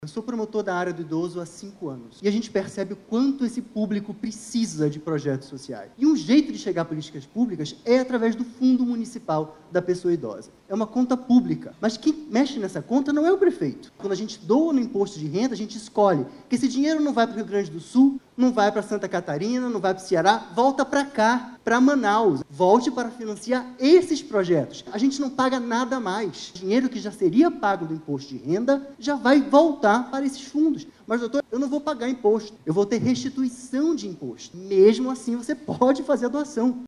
De acordo com o Promotor de Justiça, Vítor Fonseca, o lançamento da campanha é uma oportunidade para engajar a comunidade em uma ação de solidariedade.